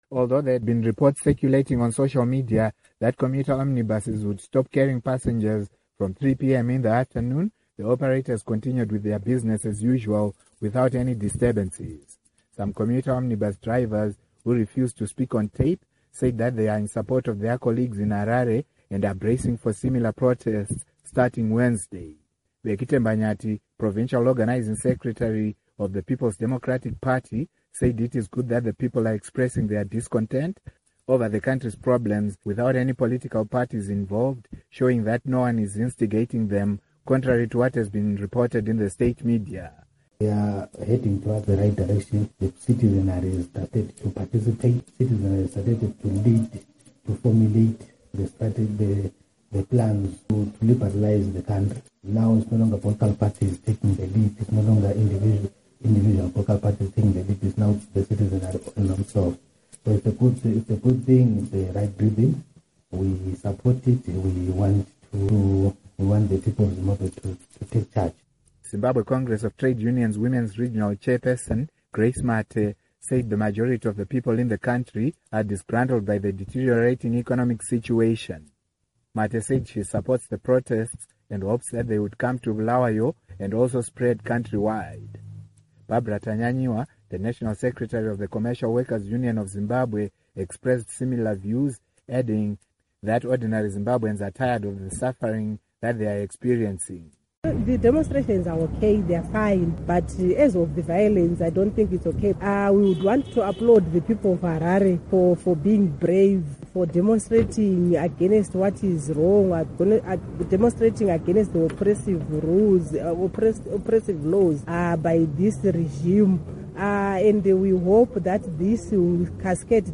Report on Riots